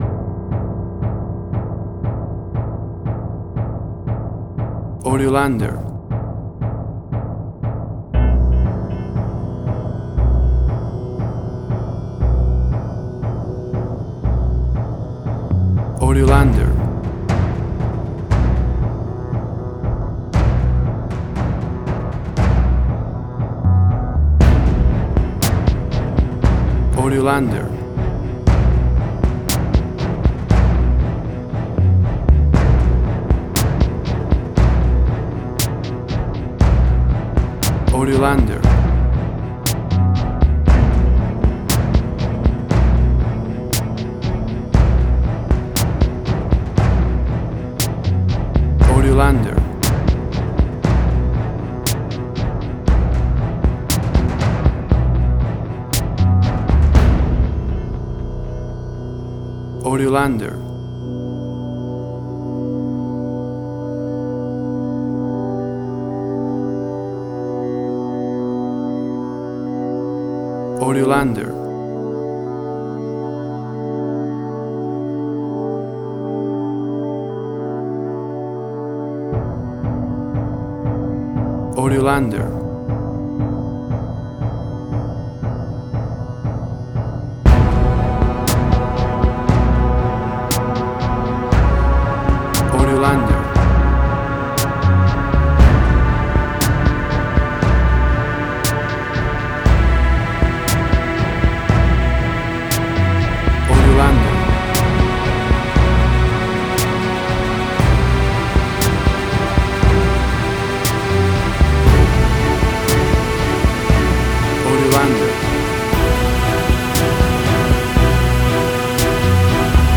Suspense, Drama, Quirky, Emotional.
Tempo (BPM): 118